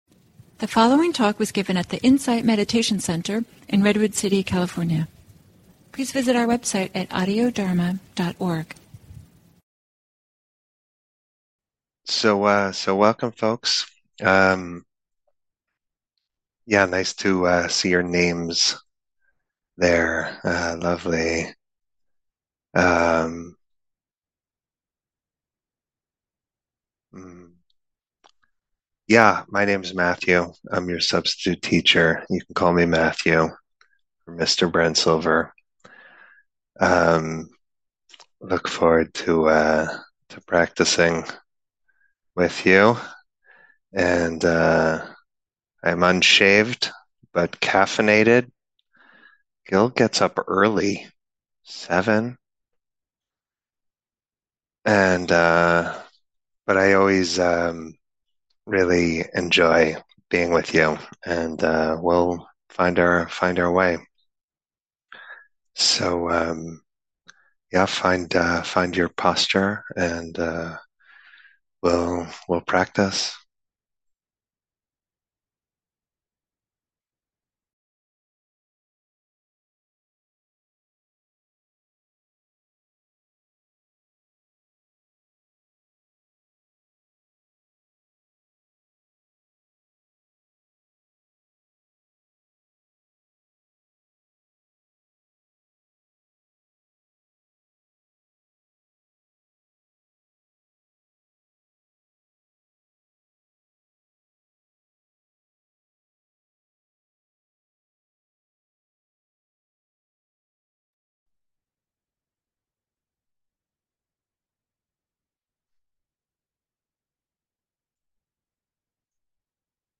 Guided Meditation: Clinging is complicated, letting go simplifies